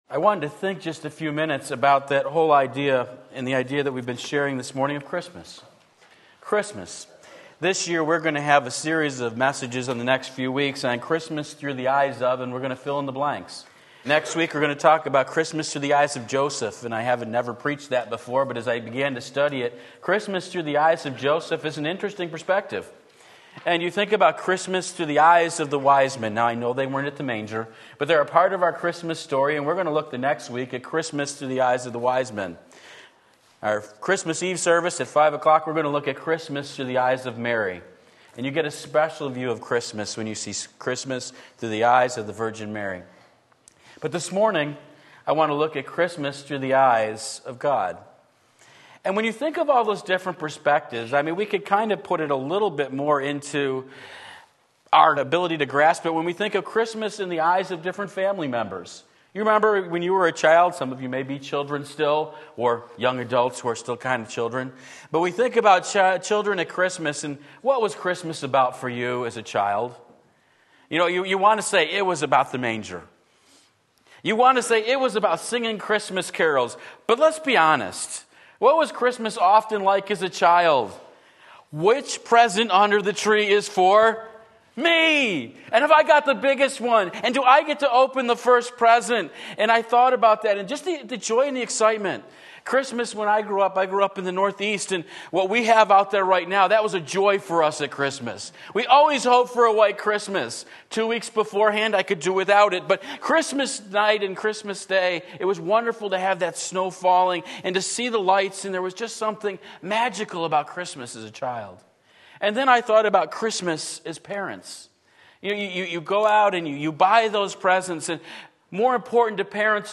Sermon Link
Jesus, the Real Meaning of Christmas Various References Sunday Morning Service, December 10, 2017 Christmas, Through the Eyes of...